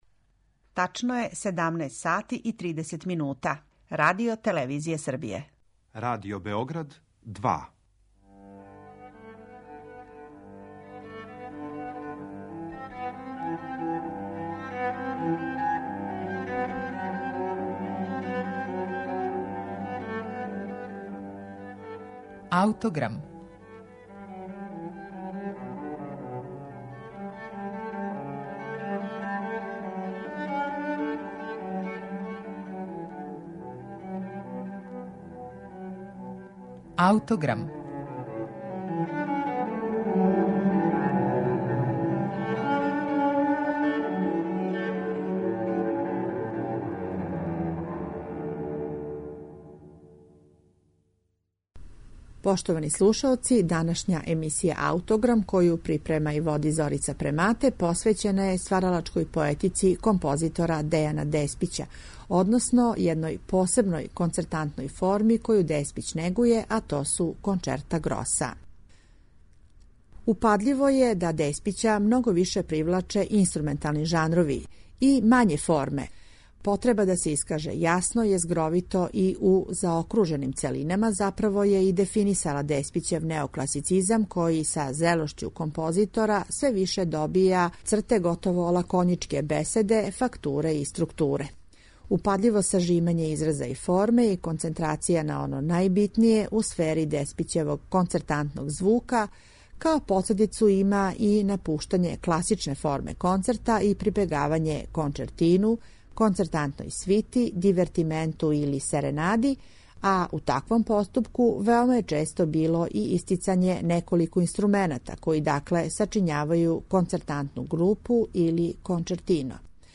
Његов неокласични стил, коме је остао веран током скоро седам деценија дуге уметничке каријере, биће сагледан као савремен и креативан допринос овој барокној концертантној форми. Емитоваћемо Кончертино за кларинет и фагот, Кончерто гросо за флауту, обоу, кларинет и фагот, Дивертименто кончертанте за кларинет, хорну, трубу и гудаче и Кончертино за две флауте и камерни оркестар.